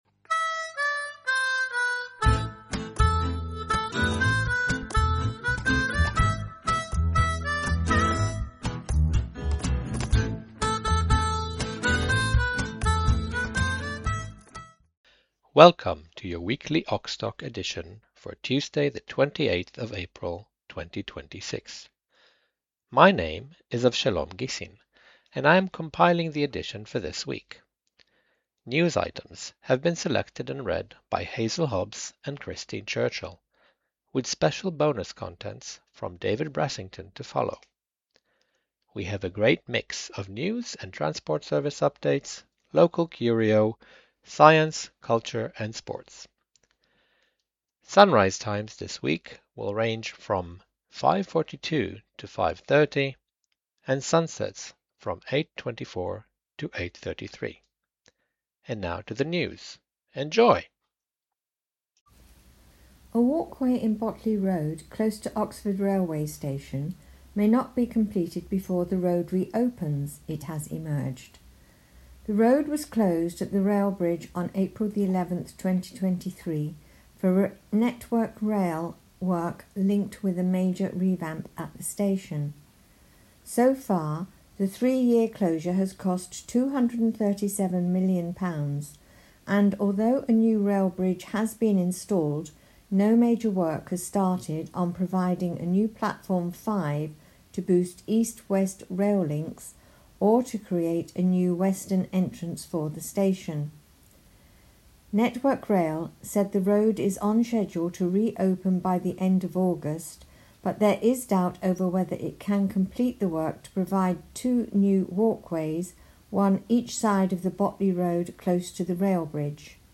28th April 2026 edition - Oxtalk - Talking newspapers for blind and visually impaired people in Oxford & district
Talking News: 28th April 2026